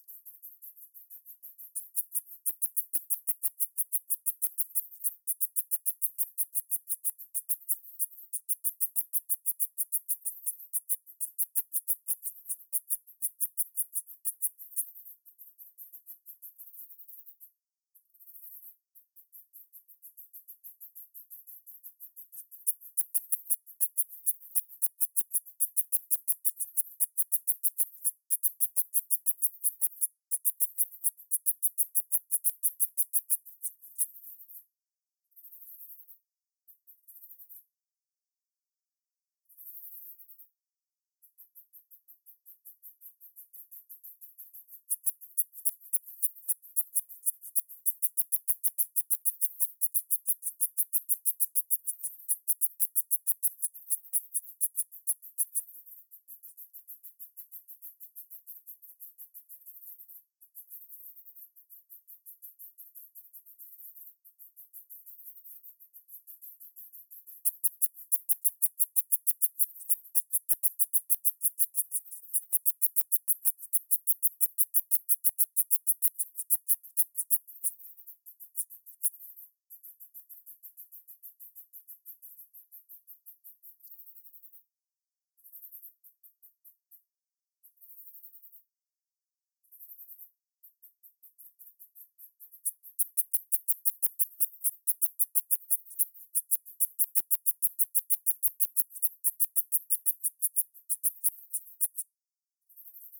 1 min 43 s of calling song from Malibu, Los Angeles County, California; 26.5 °C.